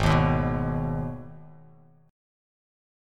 Abm Chord
Listen to Abm strummed